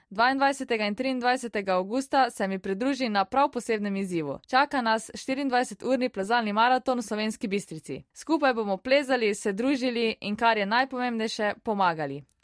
Janja maraton_Radijski oglas_1.mp3